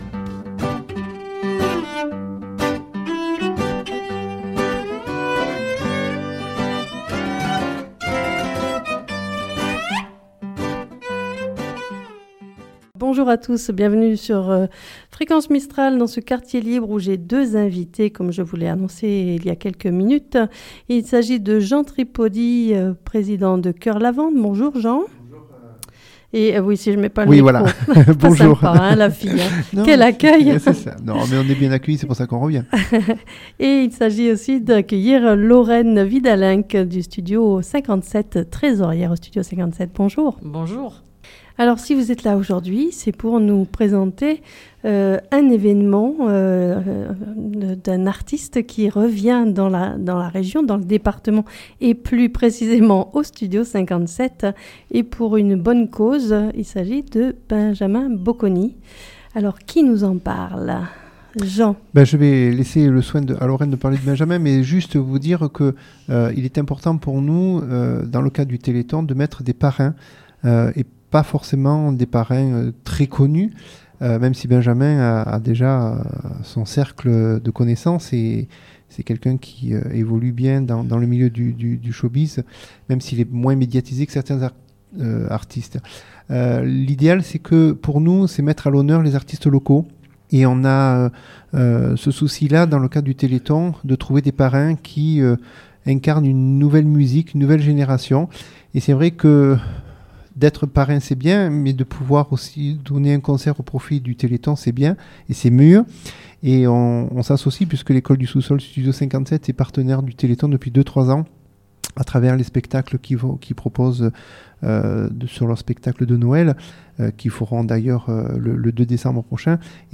Je vous propose de les écouter dans cette émission Quartier Libre.